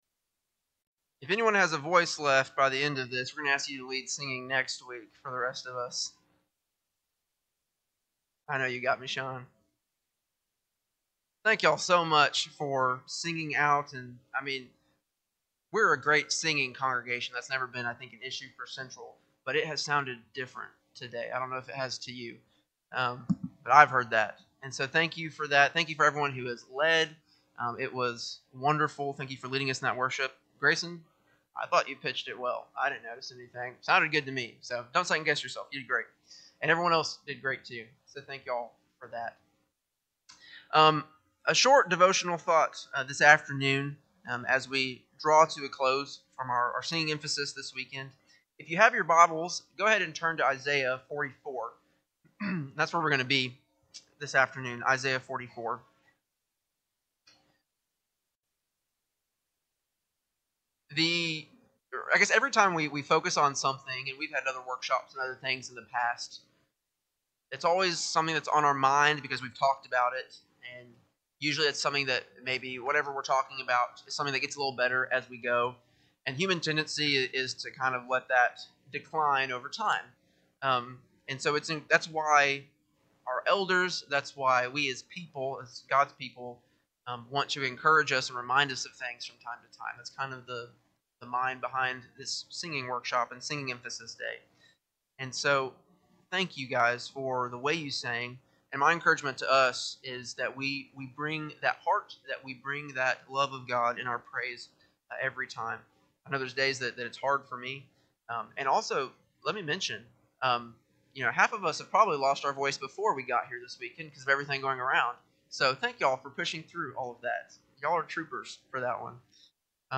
Sunday-PM-Worship-3-8-26.mp3